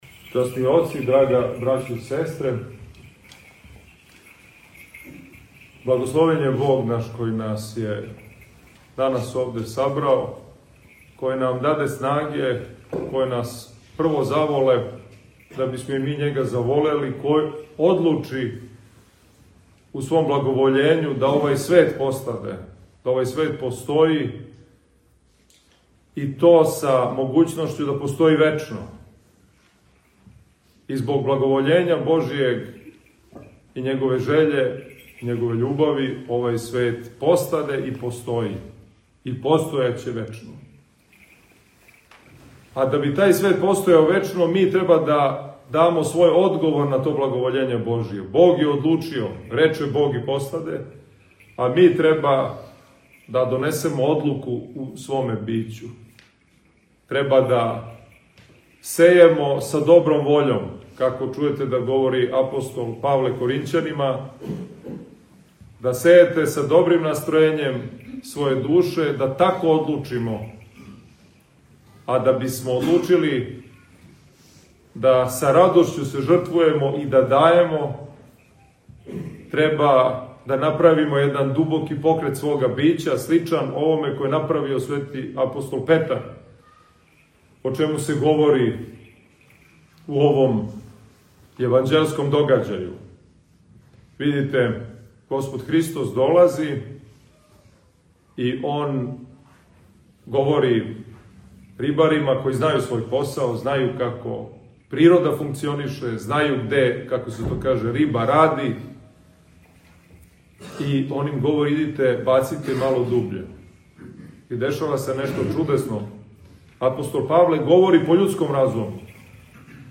У оквиру серијала „Са амвона“, доносимо звучни запис беседе коју је Његово Преосвештенство Епископ новобрдски г. Иларион, викар Патријарха српског, изговорио у осамнаесту недељу по празнику свете Педесетнице, 29. септембра / 12. октобра 2025. године. Епископ Иларион је беседио на светој Литургији у храму Свете Тројице у Заклопачи надомак Београда.